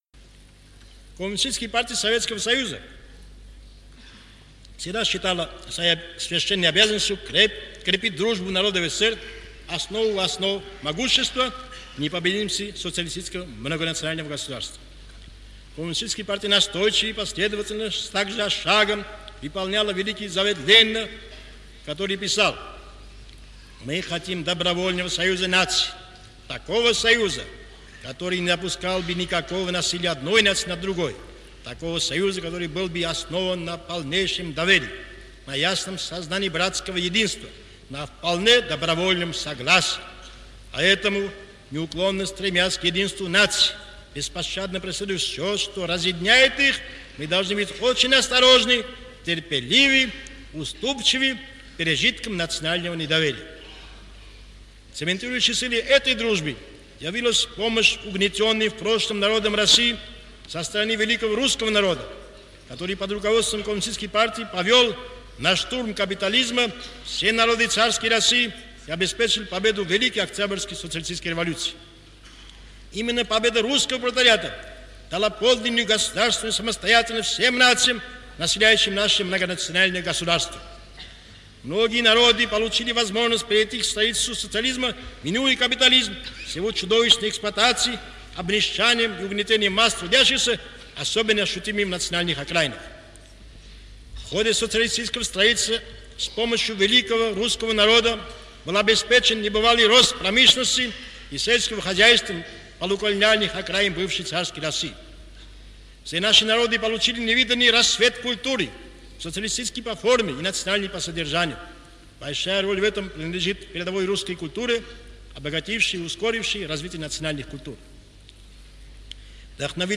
In a speech in the Armenian capital Yerevan on 11 March 1954, Mikoyan became the first Soviet official to articulate a different approach toward the nationality policy after Stalin.